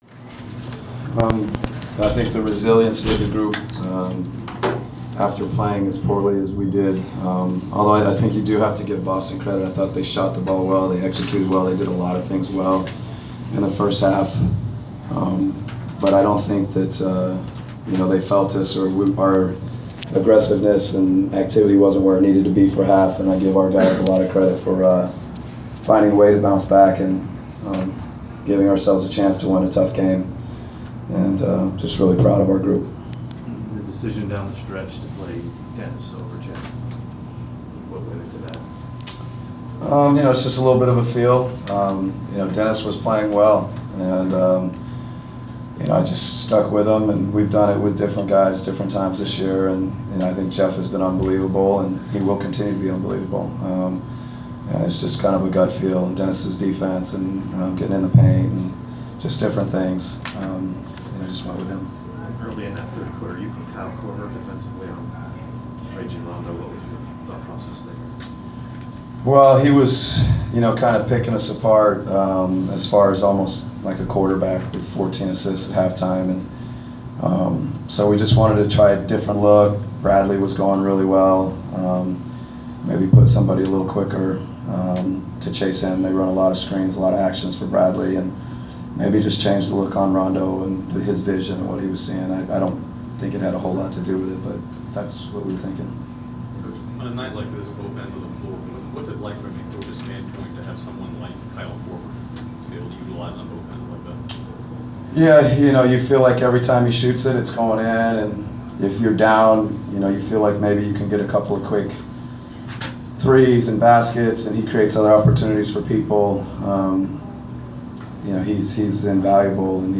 Inside the Inquirer: Postgame press conference with Atlanta Hawks’ coach Mike Budenholzer (12/2/14)
We were at the postgame press conference of Atlanta Hawks’ coach Mike Budenholzer following his team’s 109-105 home win over the Boston Celtics on Dec. 2.